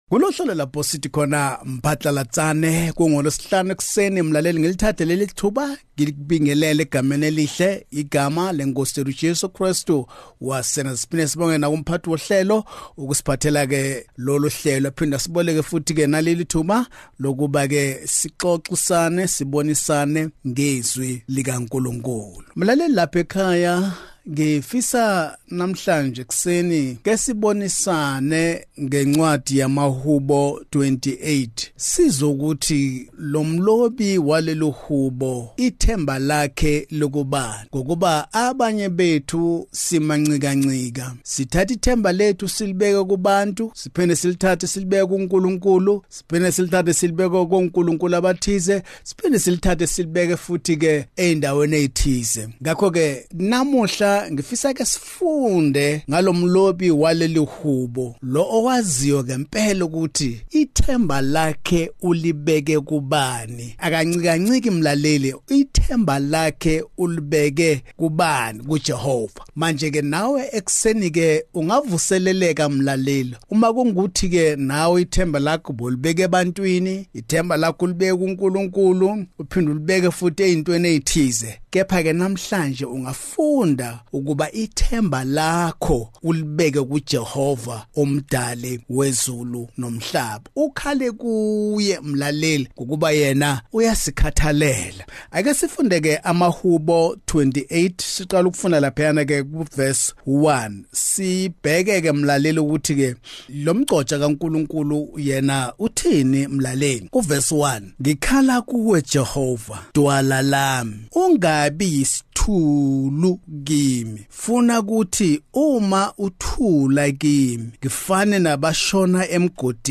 Listeners can expect a fresh Word from God early in the morning every weekday. Pastors from different denominations join us to teach the Word of God.